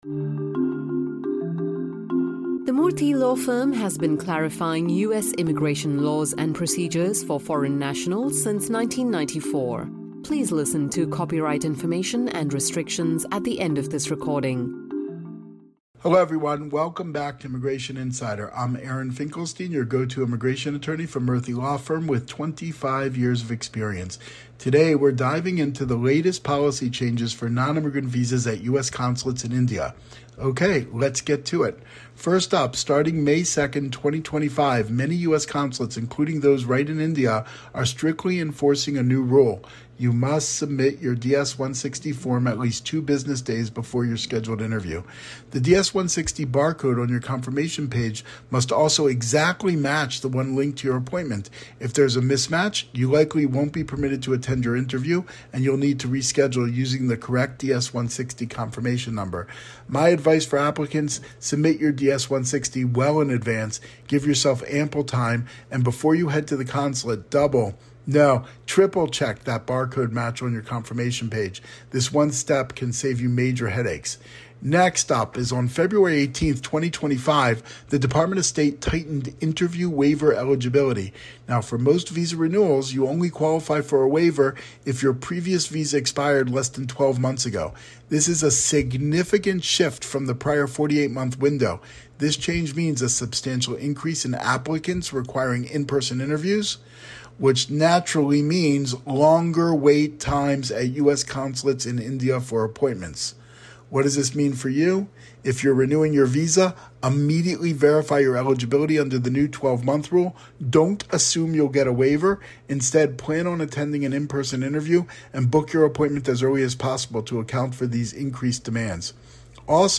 The latest policy changes for nonimmigrant visas at U.S. consulates in India are the topic today. This brief presentation by an experienced senior attorney at the Murthy Law Firm brings listeners up to date on this important subject.